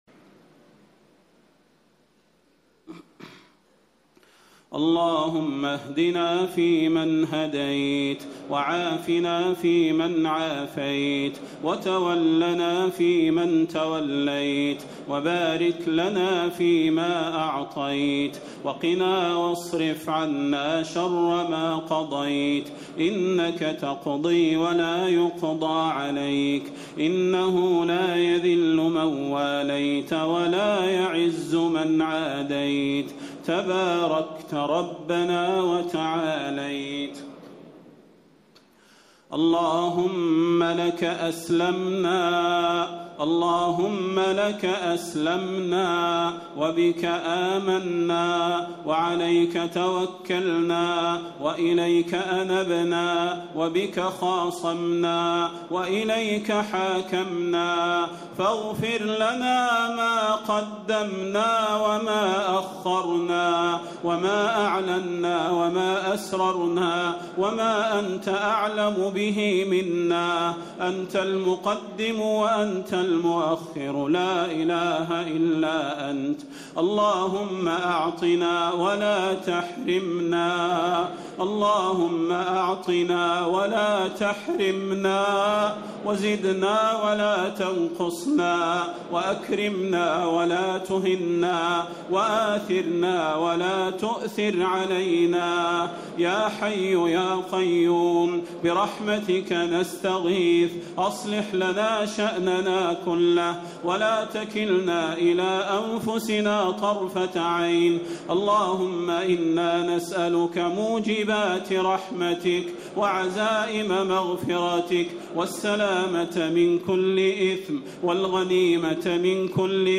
الدعاء
المكان: المسجد النبوي الشيخ: فضيلة الشيخ د. صلاح بن محمد البدير فضيلة الشيخ د. صلاح بن محمد البدير الدعاء